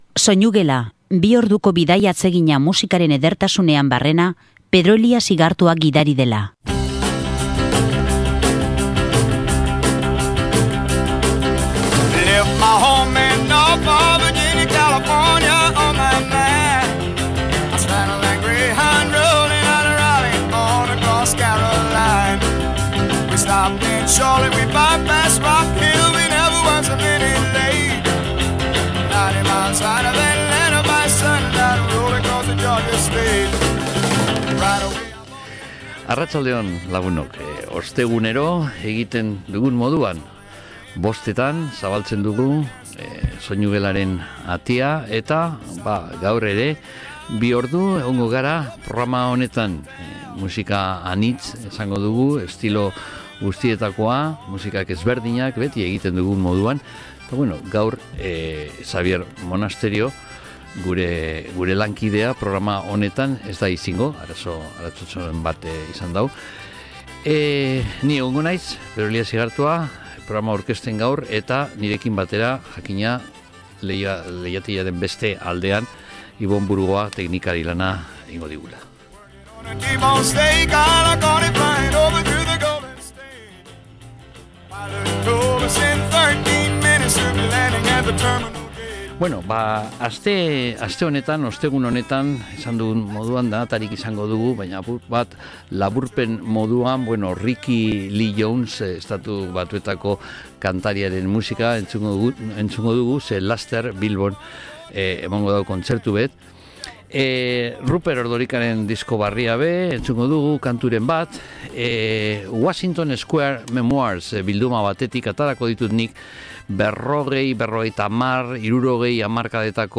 Bigarren ordua, aldiz, 50eko Folk musikari eskaini dio.